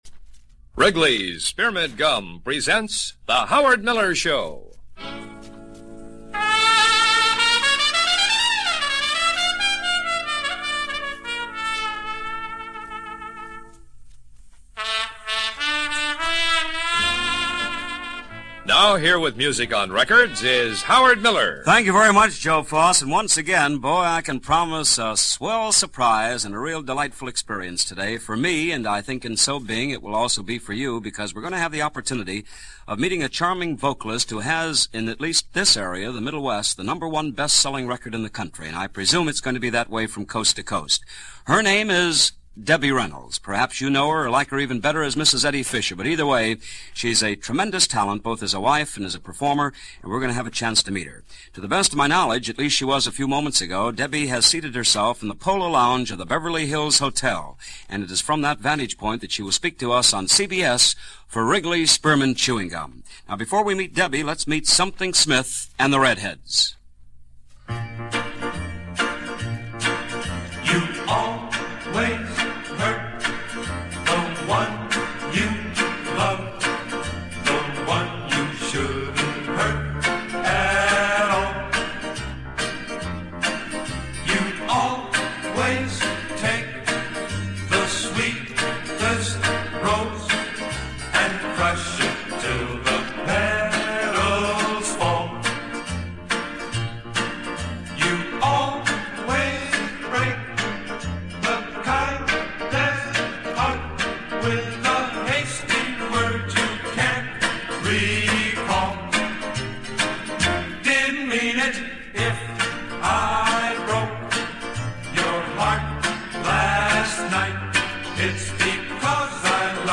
An interview with Debbie Reynolds from August 20, 1957 as done for Howard Miller and his CBS Radio morning program.